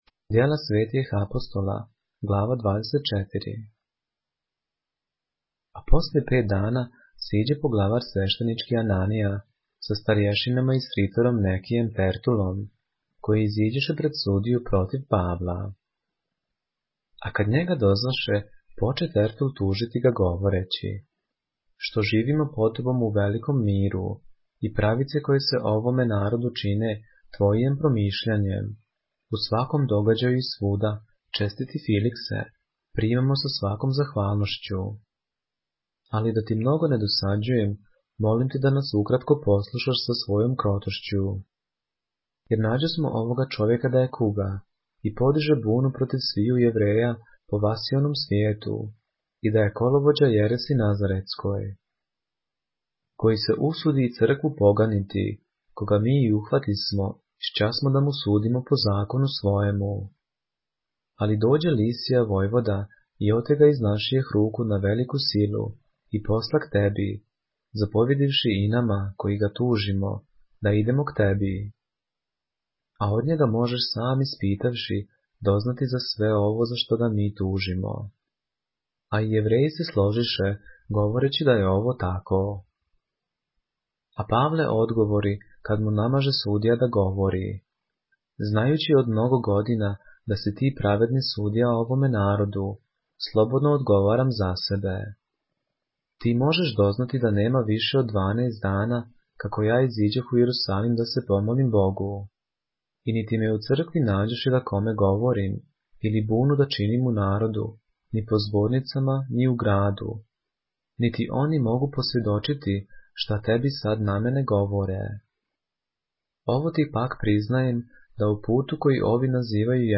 поглавље српске Библије - са аудио нарације - Acts, chapter 24 of the Holy Bible in the Serbian language